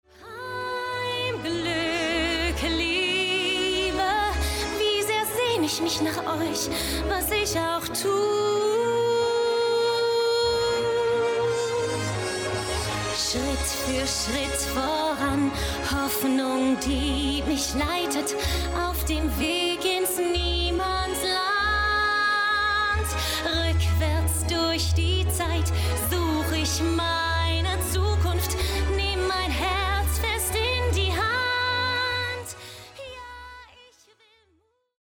Vocals (Gesang)